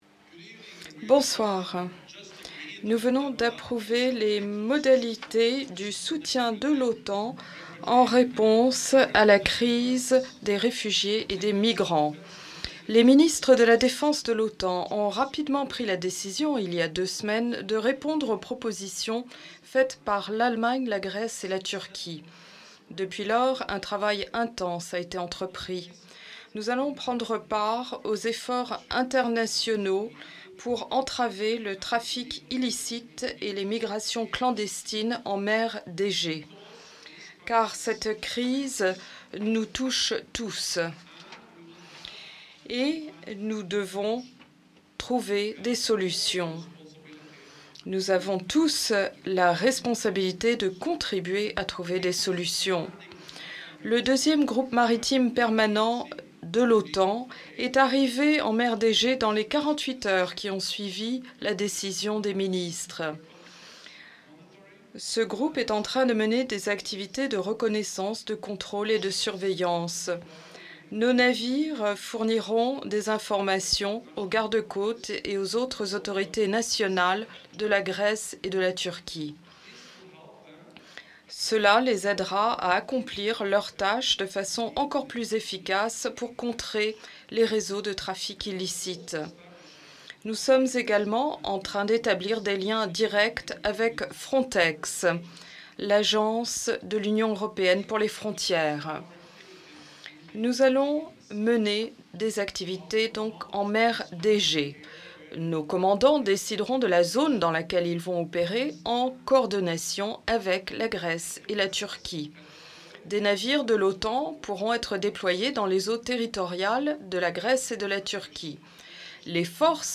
ENGLISH: Statement by NATO Secretary General Jens Stoltenberg on NATO support to assist with the refugee and migrant crisis 25 Feb. 2016 | download mp3 FRENCH: Statement by NATO Secretary General Jens Stoltenberg on NATO support to assist with the refugee and migrant crisis 25 Feb. 2016 | download mp3